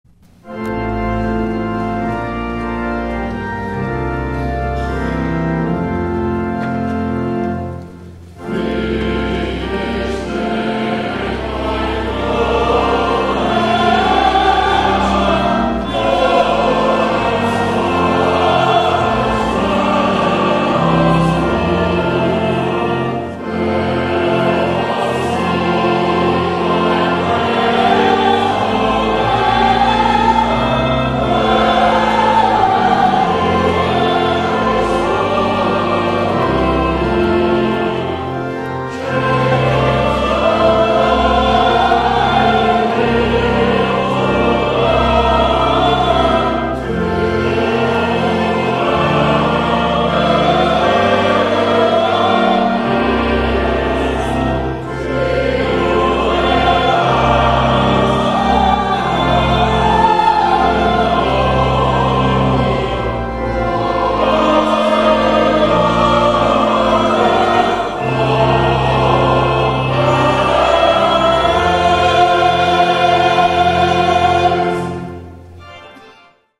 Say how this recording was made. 11 A.M. WORSHIP